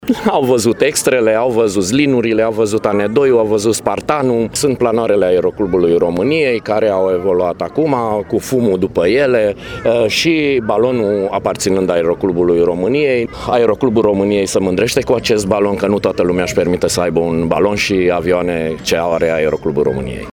Acestea sunt doar câteva dintre atracțiile celei de-a VII – a ediții a mitingului aviatic „Sky is not the limit”, care a avut loc sâmbătă.